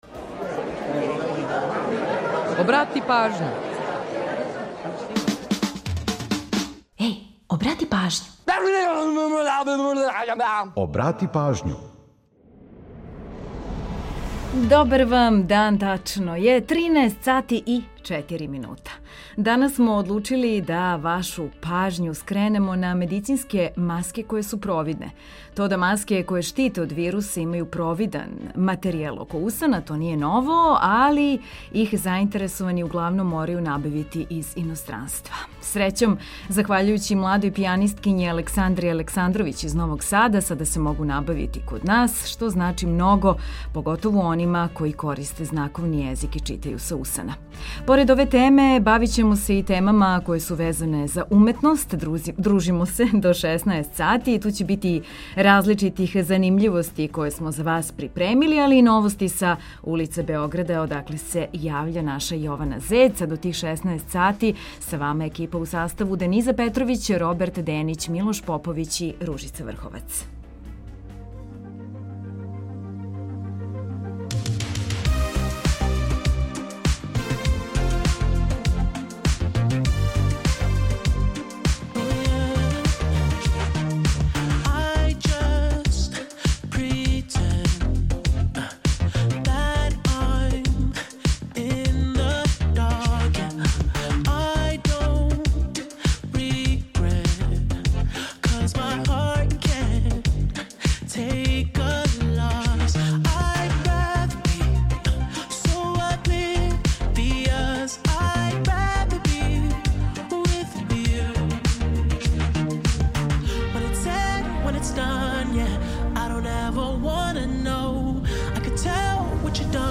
До краја емисије бавићемо се музиком и различитим занимљивостима.